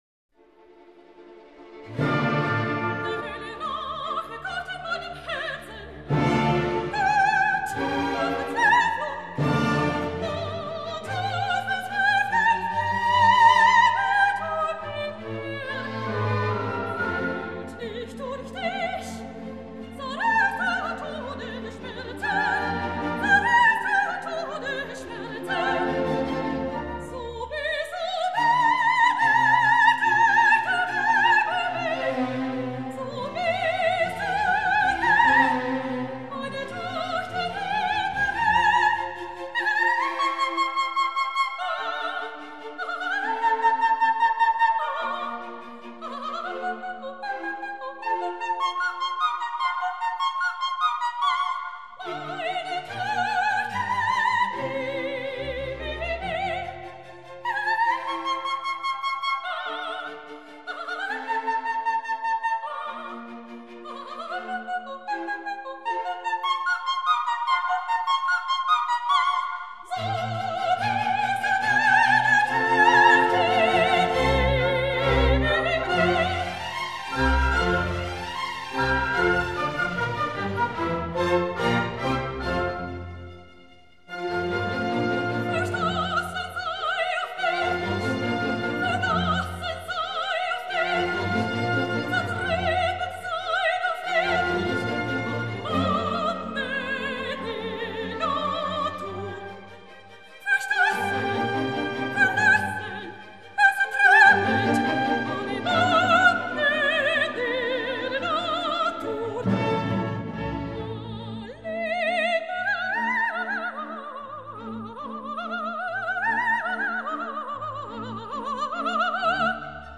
Aria de la reina de la noche.mp3
magic flute      Monostatos      opera      Pamina      Papageno      Princess Pamina      Tamino      the magic flute      the three ladies      The Three Ladies      Three Ladies